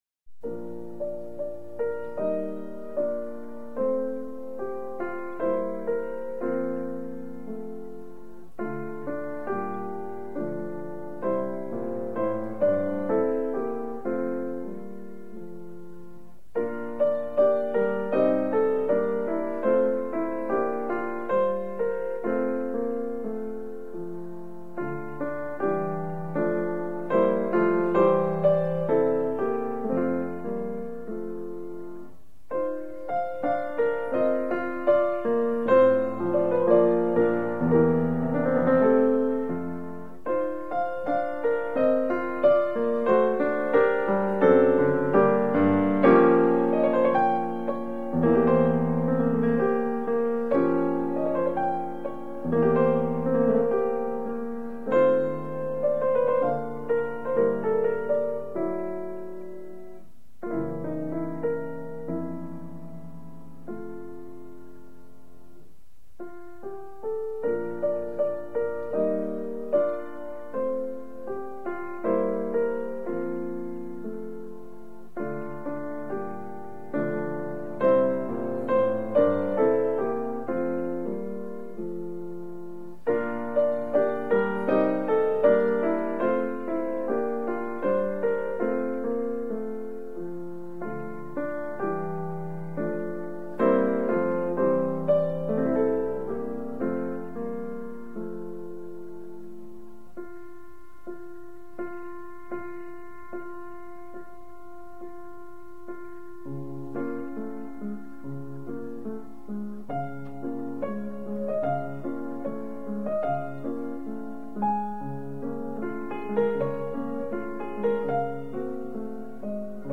0238-钢琴名曲如歌的行板.mp3